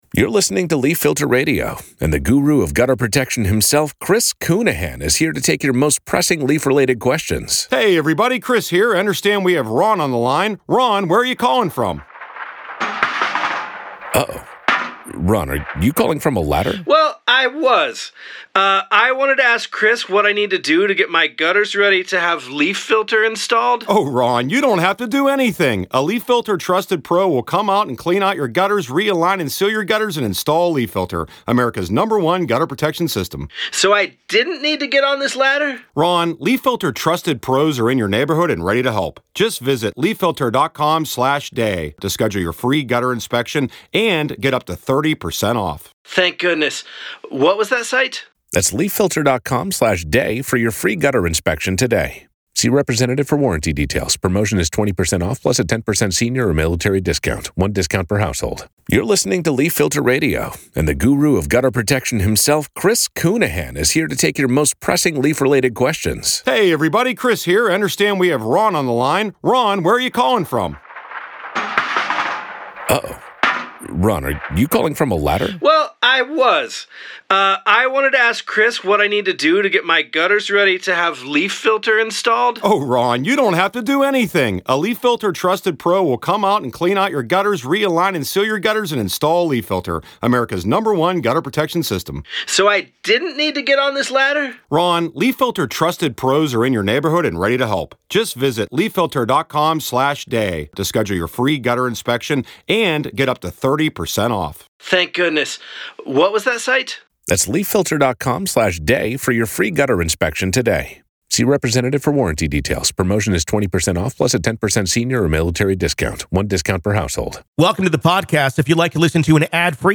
True Crime Today | Daily True Crime News & Interviews